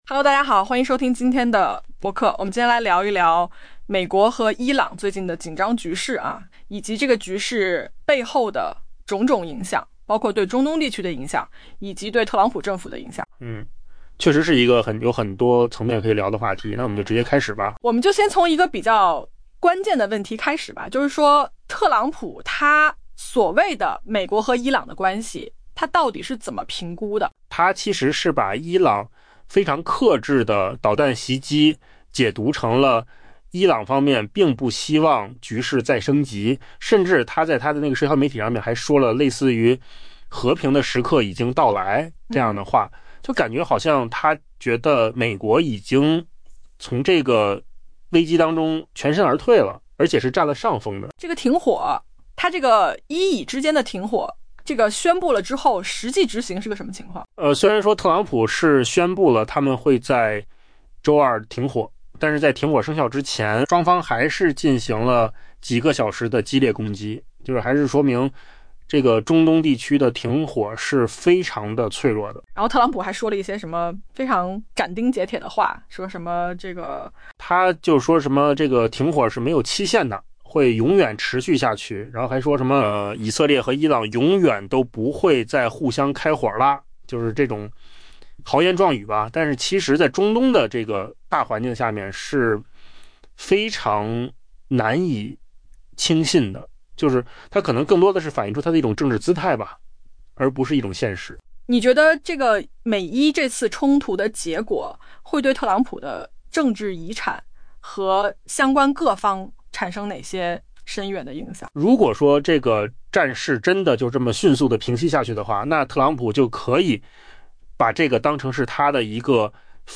AI播客：换个方式听新闻（音频由扣子空间生成） 下载mp3